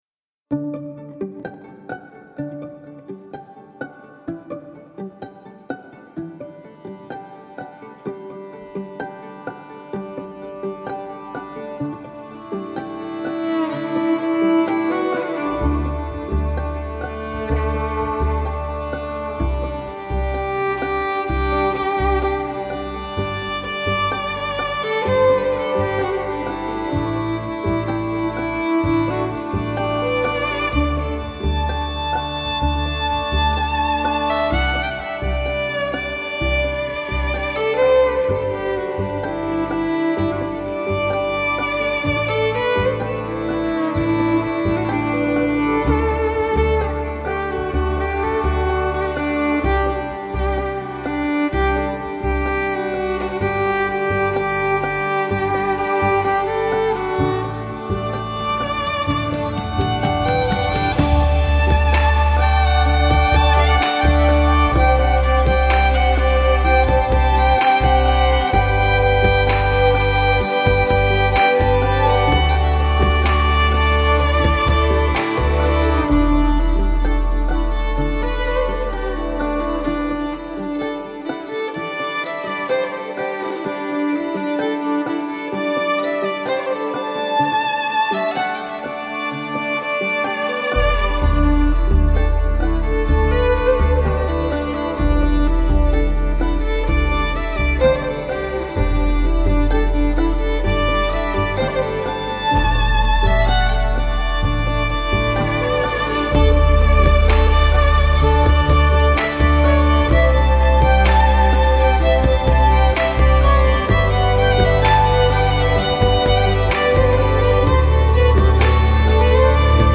• Classically trained violinist specialising in live looping
• A unique combination of violin and beatboxing
• Covers (Loop Pedal)
Violin, Loop pedals, Beat boxing